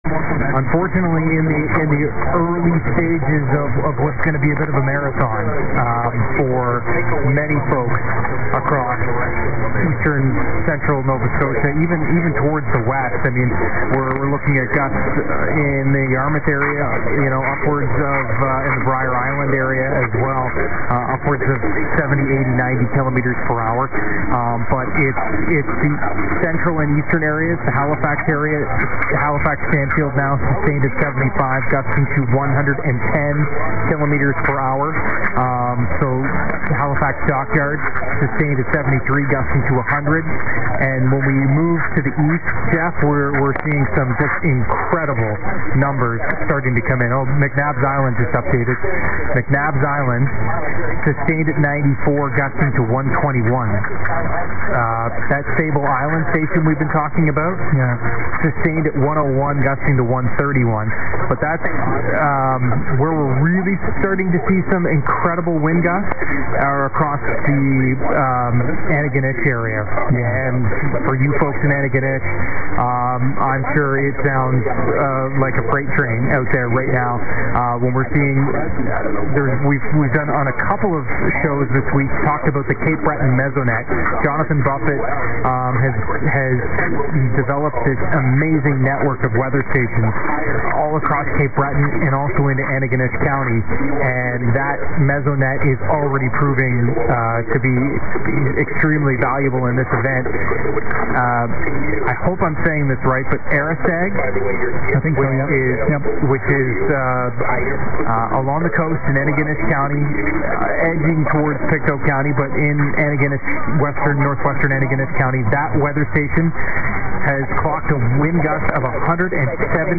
As some of you may have difficulty hearing stations from Nova Scotia on regular radio, I thought I’d pass along this short audio clip of Hurricane Fiona coverage from 1140 CBI Sydney, NS as heard here.